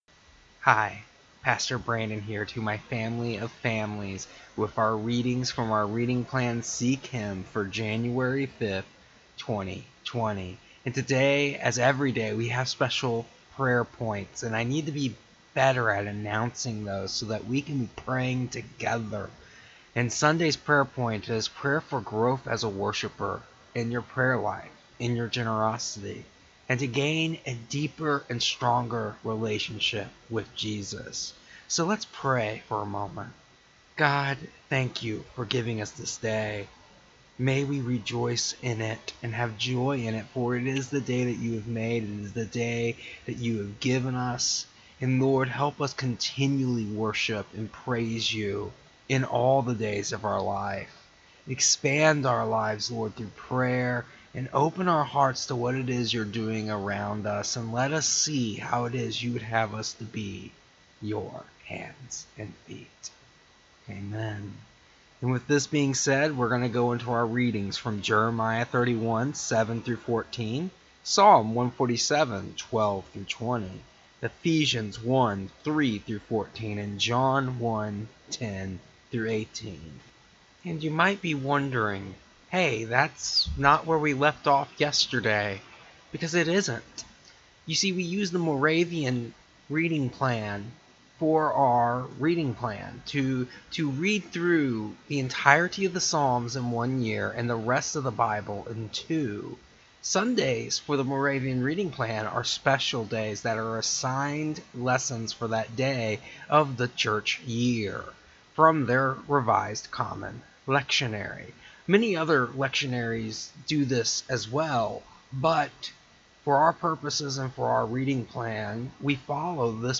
Our readings today were: